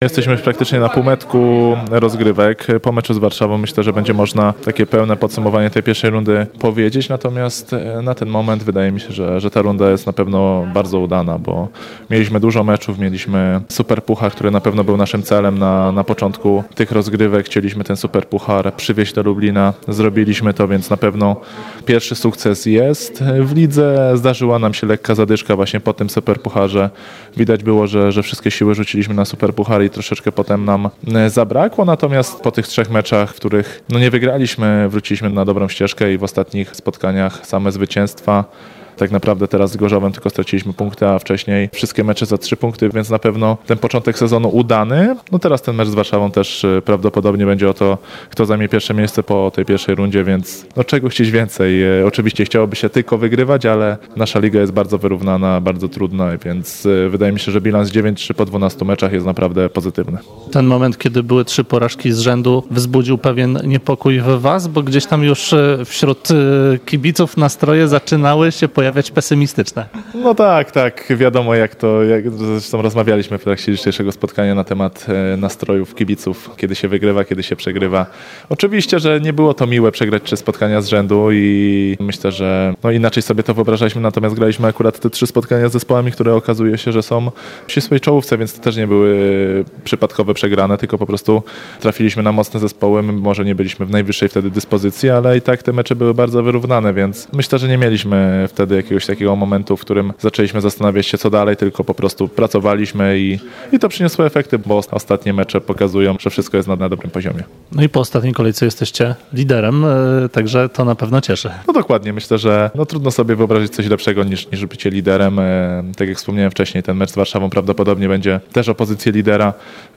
Dotychczasowe występy lubelskiego zespołu podsumowuje jego kapitan Marcin Komenda.